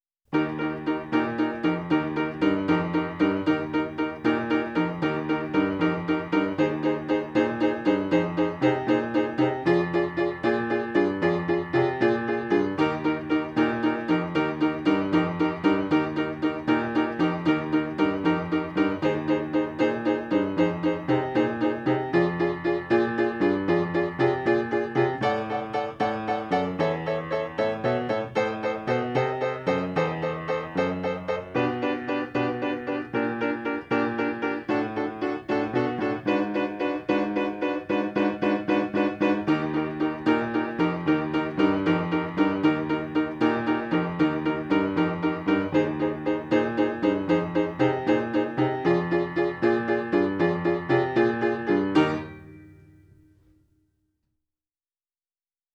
piano demo